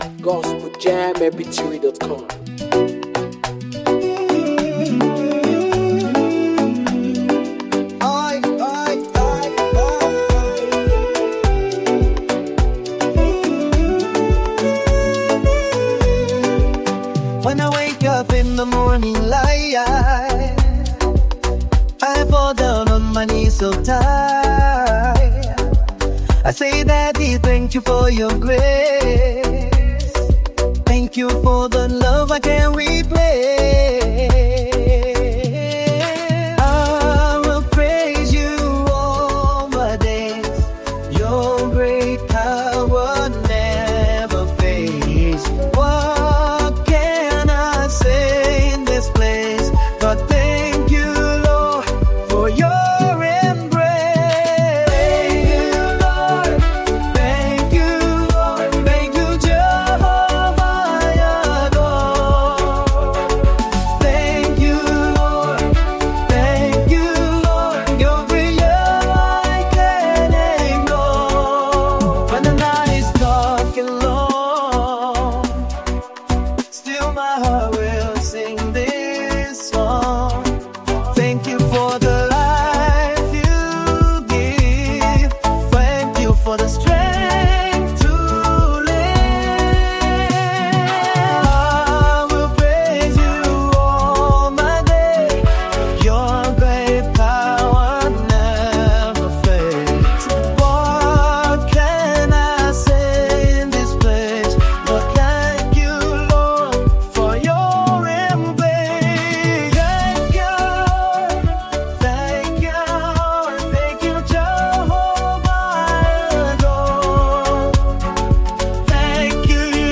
heartfelt gospel song